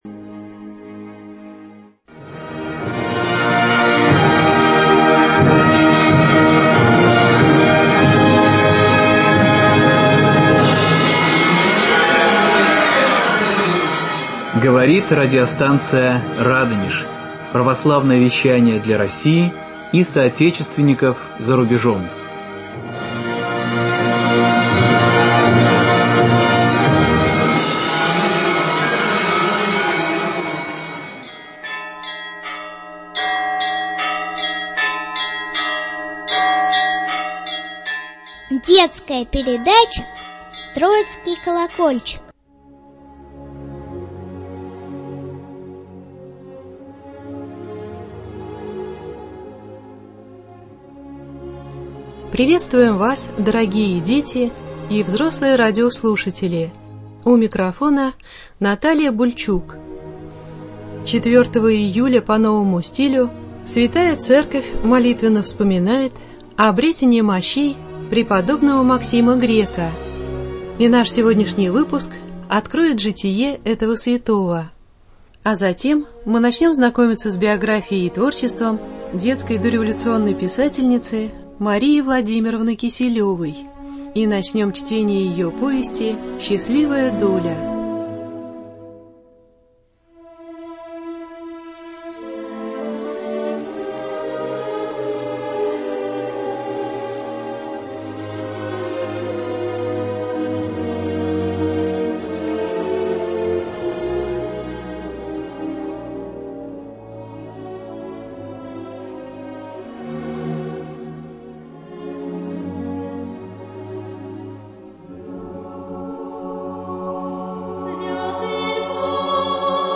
Программа построена на чтении альманахов дореволюционной литературы для детей и юношества.